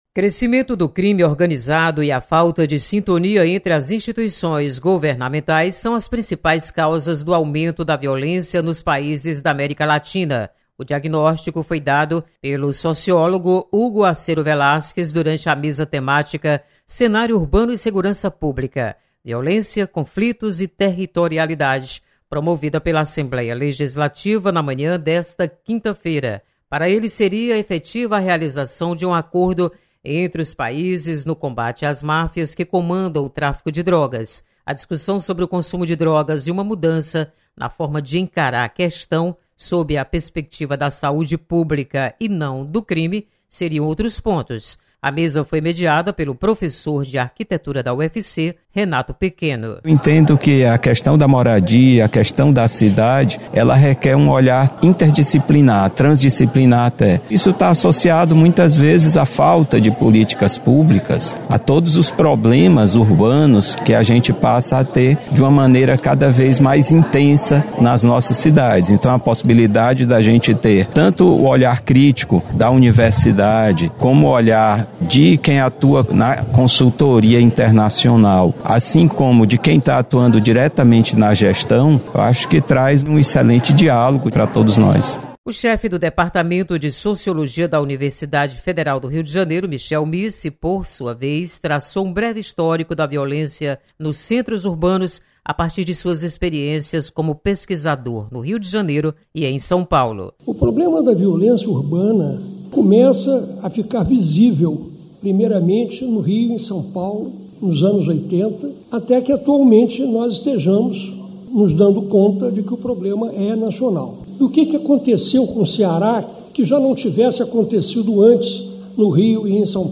Seminário Internacional sobre Segurança Pública debate sobre crescimento do crime organizado. Repórter